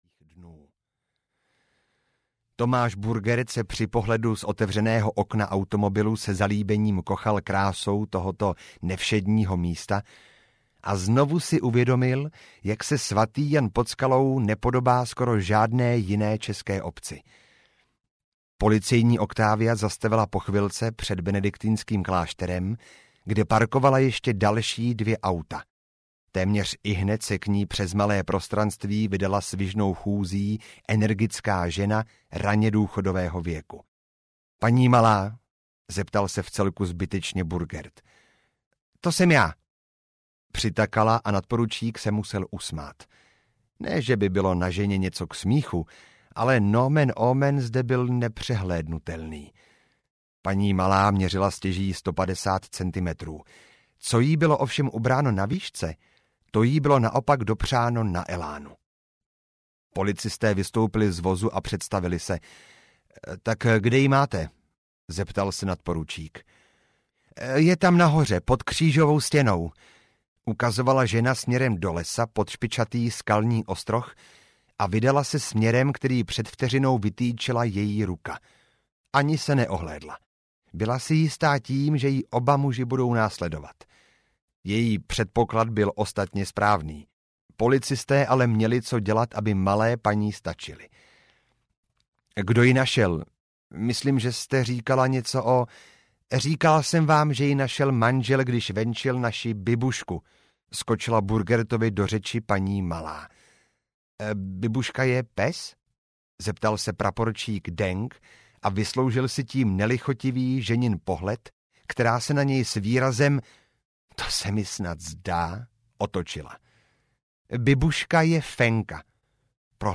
Dál nechoď! audiokniha
Ukázka z knihy